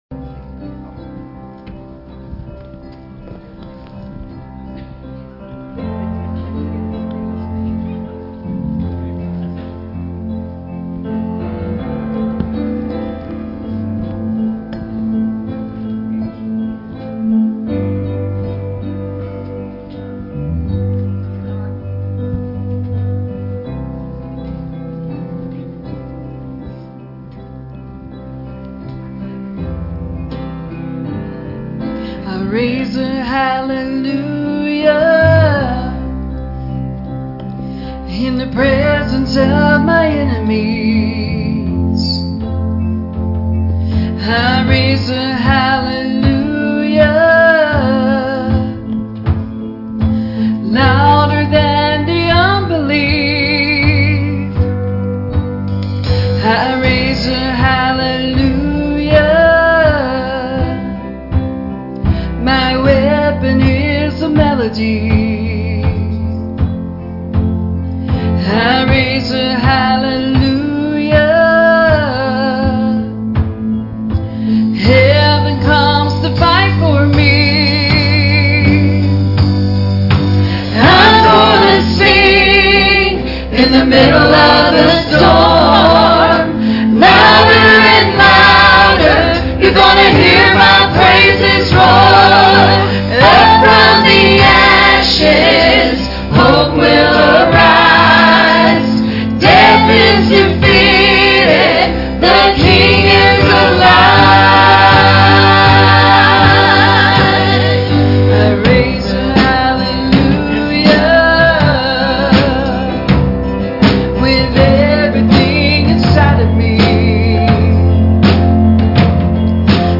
"Isaiah 7:10-14" "Matthew 1:23" Service Type: Sunday Morning Services « “What Good Shall I Do?”